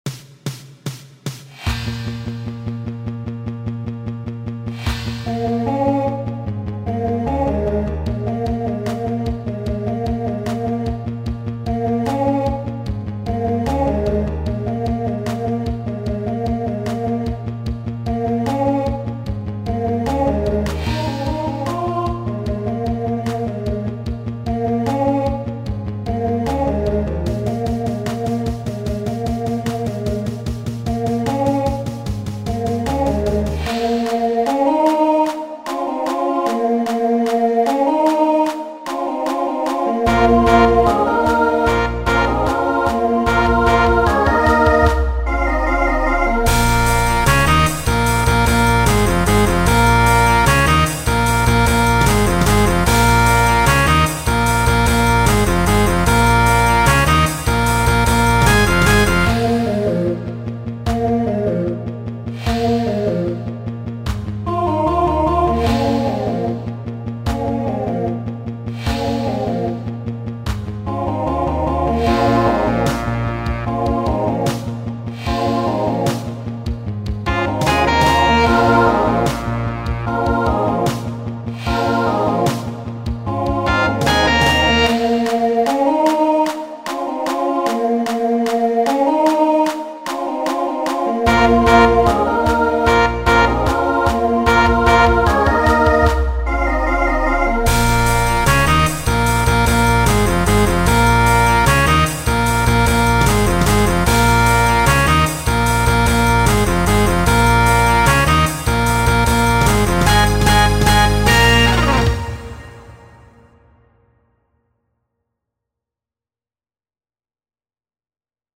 Genre Rock Instrumental combo
Transition Voicing SATB